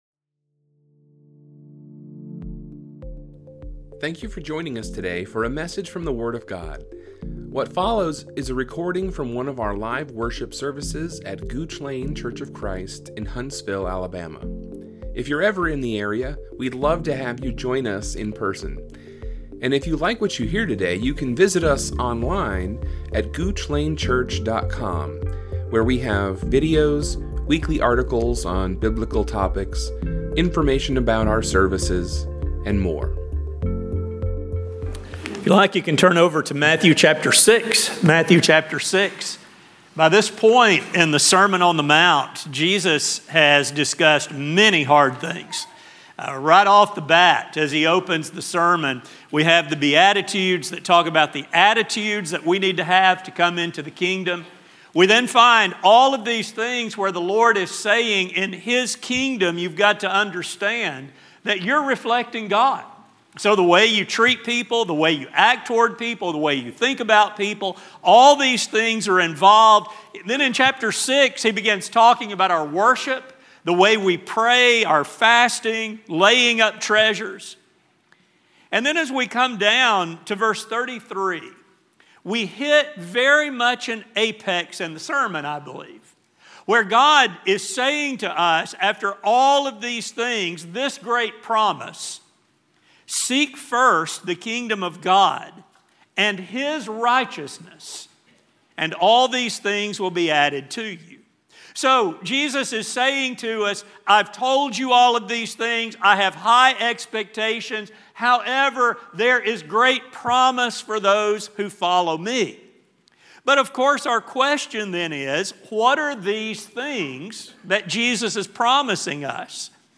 But what, exactly, will be added? This question will be explored in this sermon. The study will focus on what God has promised, His timing in fulfilling those promises, and what this means for the lives of kingdom citizens.